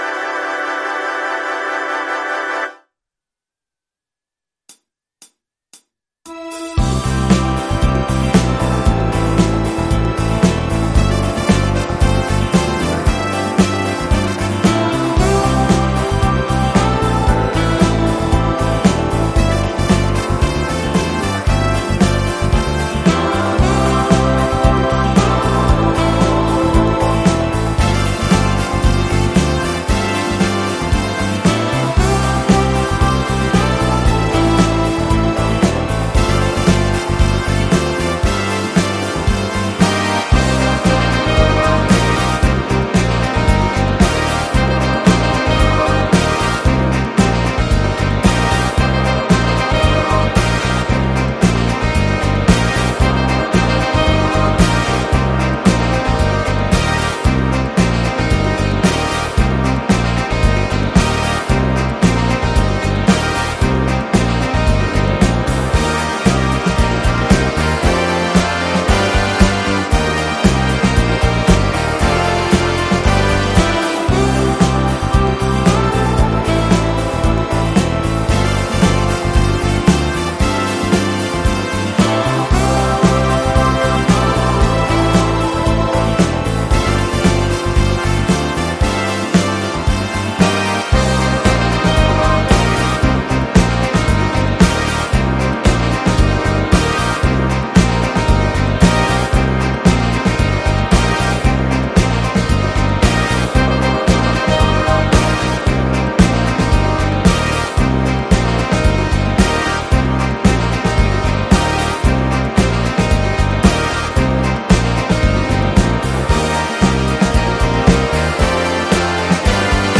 Professional musical transcription (backing track)
Track7: Clavi Track8: Rock Organ
Track15: Tenor Sax Track16: Acoustic Grand Piano Tempo: 115